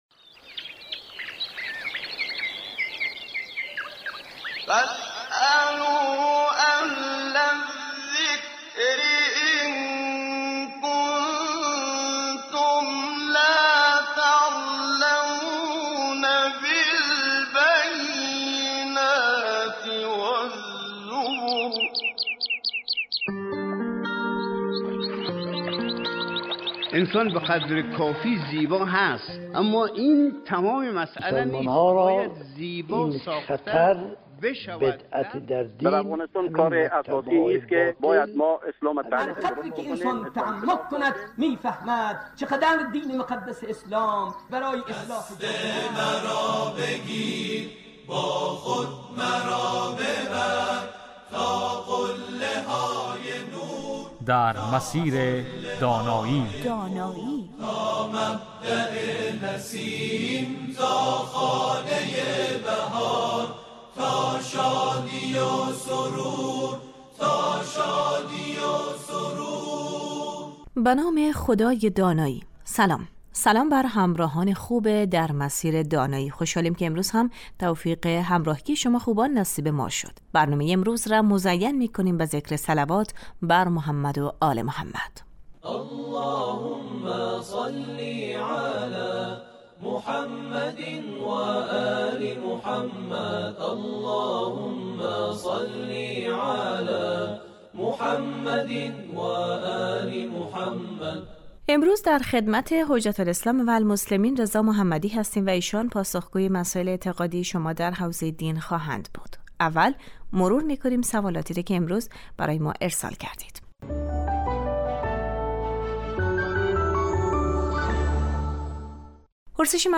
این برنامه 20 دقیقه ای هر روز بجز جمعه ها ساعت 11:35 از رادیو دری پخش می شود